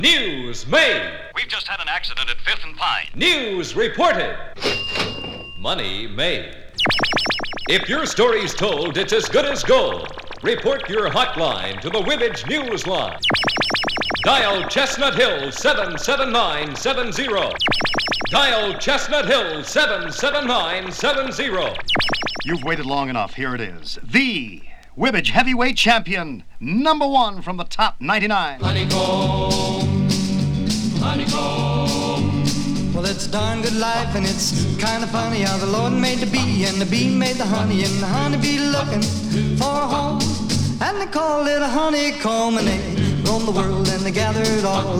※Mono音源をStereoにしています。
Rock, Pop, Rock & Roll　USA　12inchレコード　33rpm　Stereo